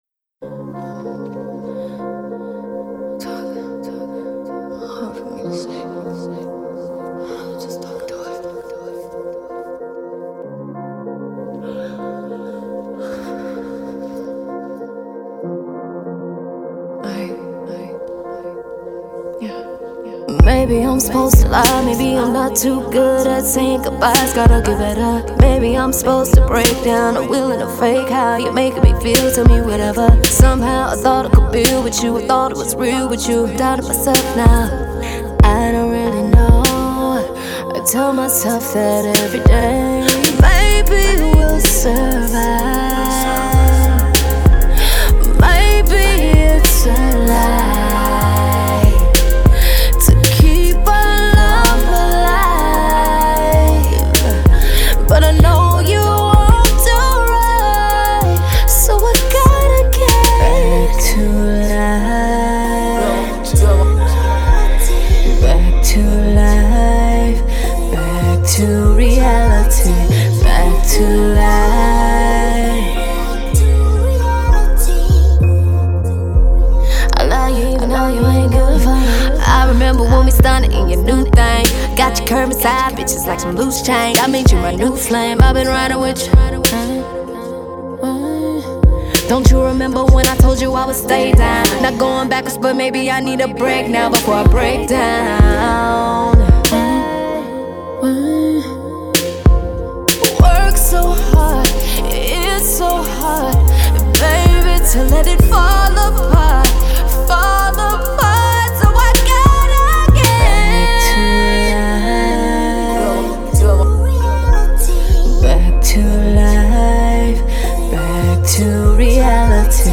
slow but with a little bit of bump in the background.
with a little more oomph and gravitas to her tone